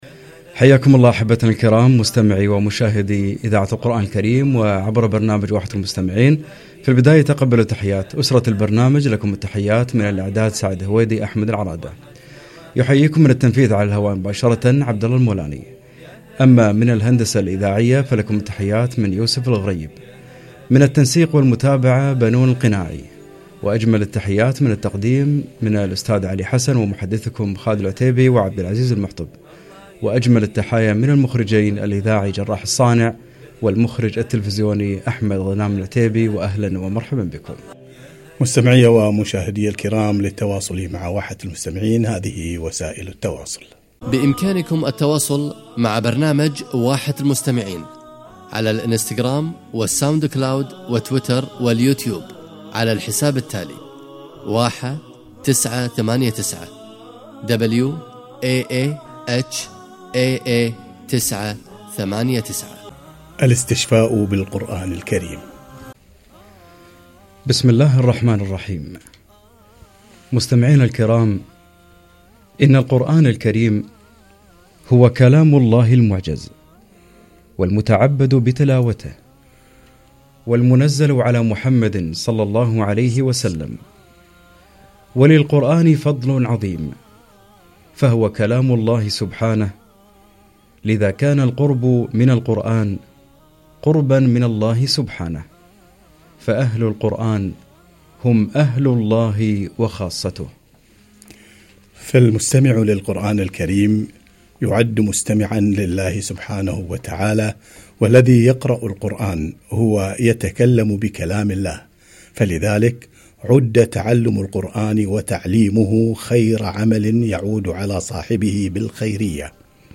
الاستشفاء بالقرآن - لقاء عبر برنامج واحة المستمعين